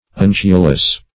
Undulous \Un"du*lous\, a.